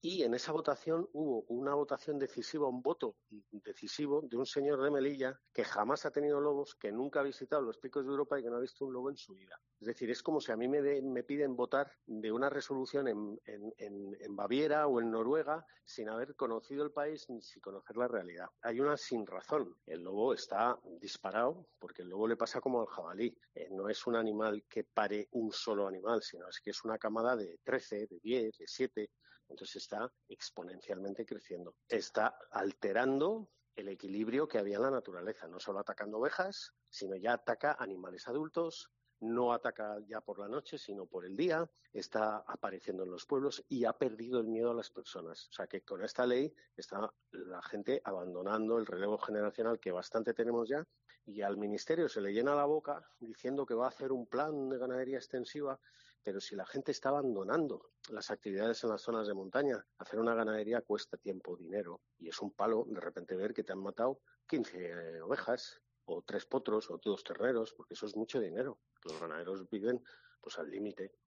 Palencia ha recordado en COPE Cantabria como el lobo se incluyó en el listado de especies de especial protección “no por una cuestión ecológica sino ideológica”.